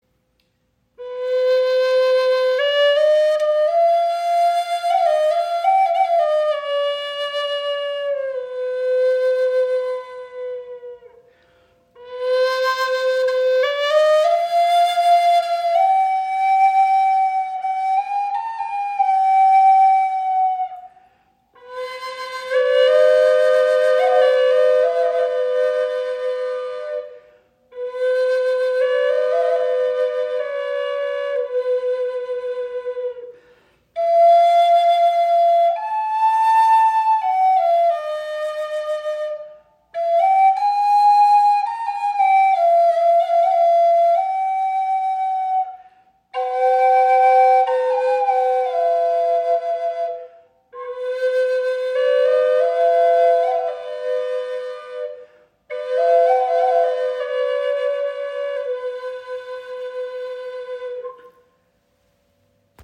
Doppelflöte in C - Aeolian - 432 Hz im Raven-Spirit WebShop • Raven Spirit
Klangbeispiel
Diese wundervolle Doppelflöte ist auf C Aeolian in 432 Hz gestimmt und erzeugt einen warmen, tragenden Klang.